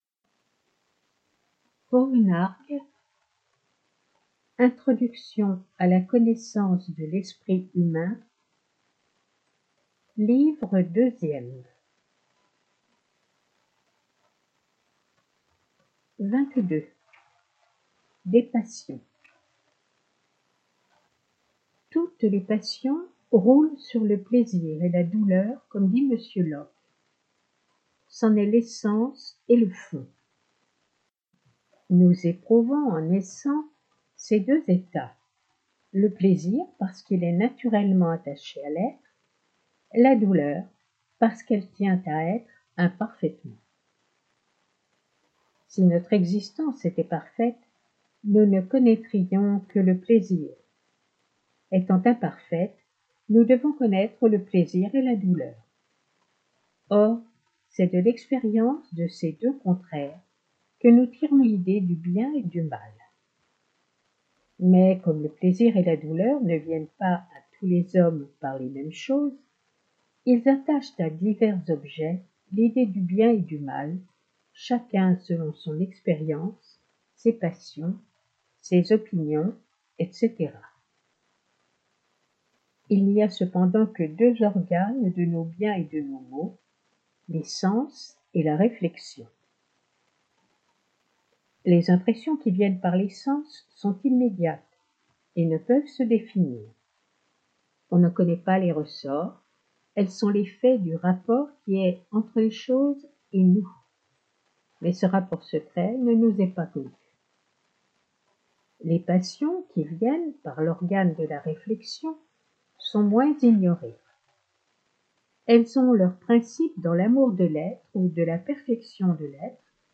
Genre : Essais